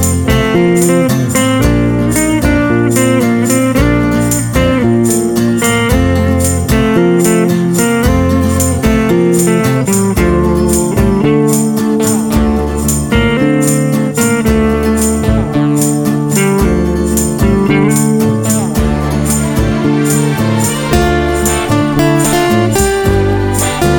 No Harmony Pop (1960s) 2:37 Buy £1.50